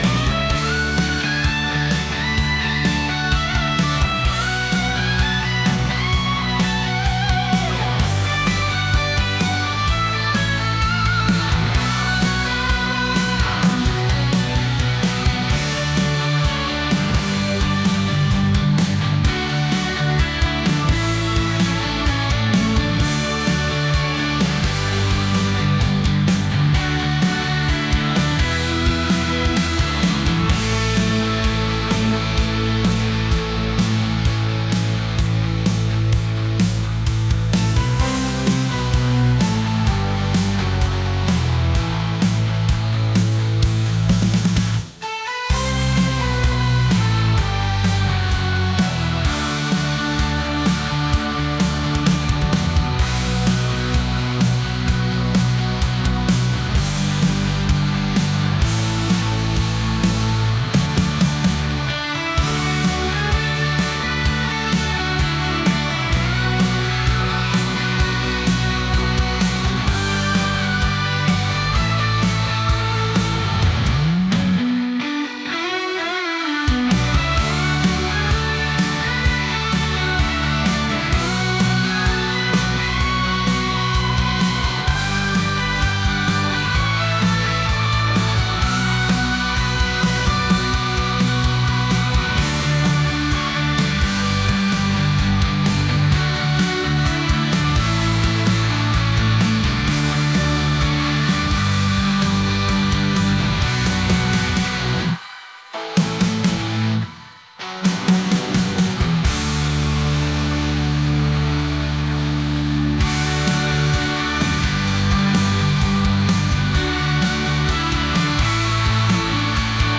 rock | energetic